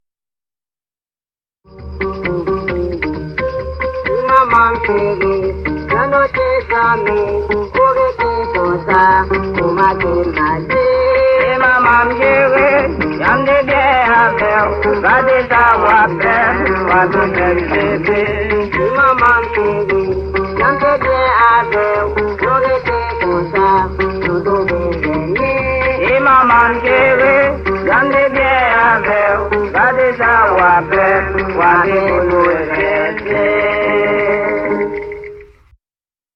Une musique de rue des zones rurales d’Haïti
“Ti manman-m chéri” (Ma petite maman chérie) par Ago’s Bal Band, Port-au-Prince (coffret Alan Lomax in Haiti, 1936-37, Harte Recordings, 2009)
Le motif rythmique de la clave joué sur les bâtons est un héritage des origines cubaines de ce groupe. Le chœur exprime une complainte suite à une rupture amoureuse :